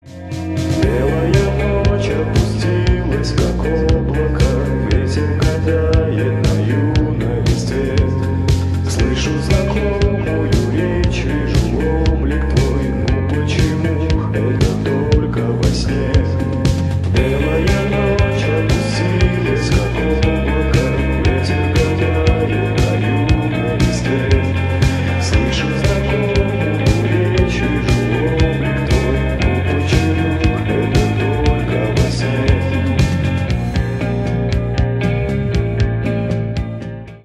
Жанр: Русская поп-музыка / Русские